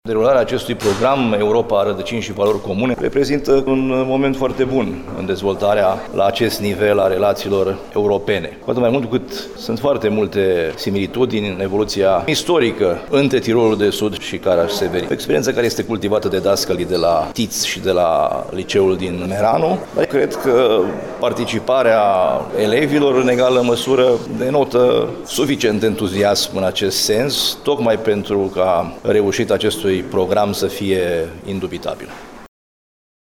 Despre proiecţ acesta a subliniat: